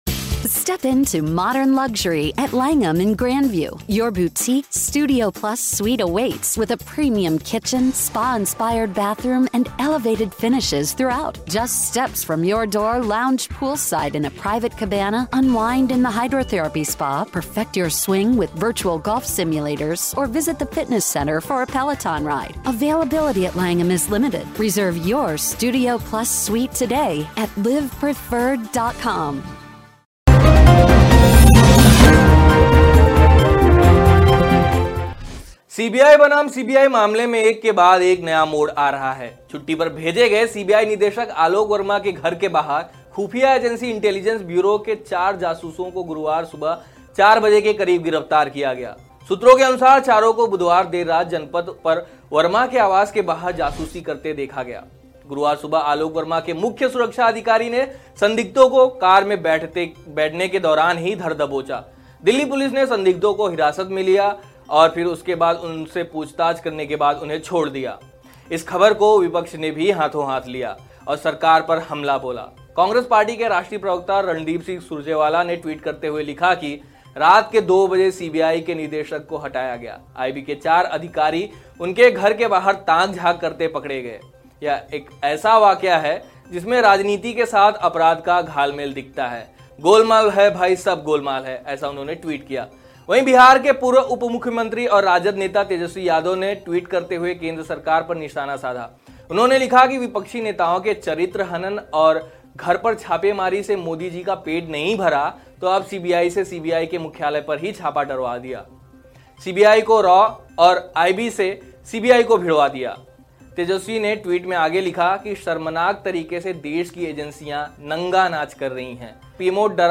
न्यूज़ रिपोर्ट - News Report Hindi / देश की एजेंसियां नंगा नाच कर रही है, PMO वसूली का अड्डा बन गया है- तेजस्वी यादव